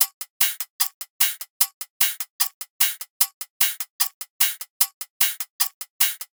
VR_top_loop_proper_150.wav